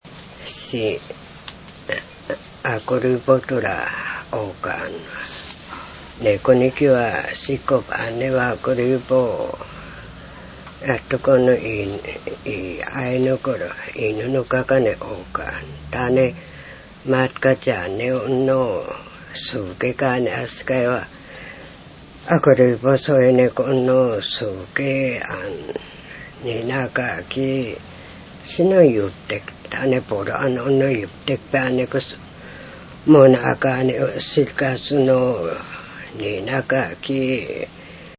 • ジャンル：民話　ウウェペケㇾ／アイヌ語